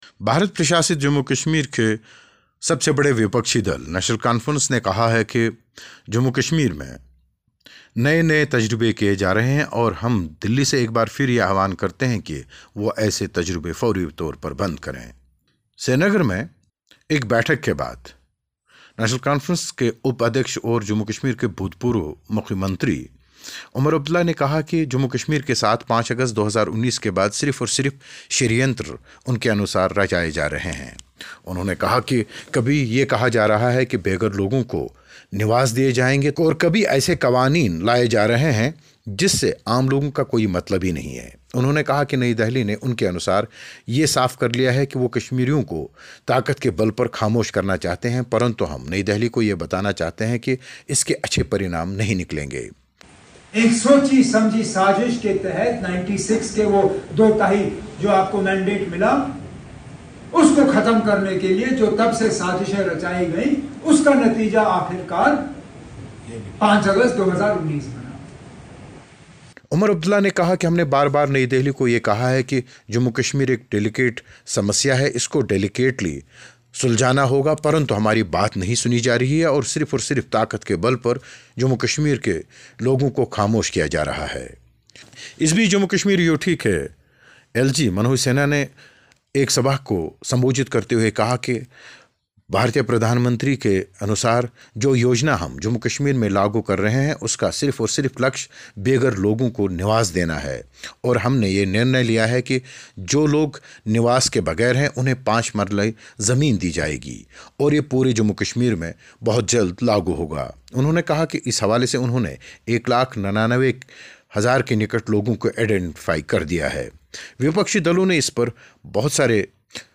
कश्मीर को कार्यशाला न बनाए नई दिल्ली, रिपोर्ट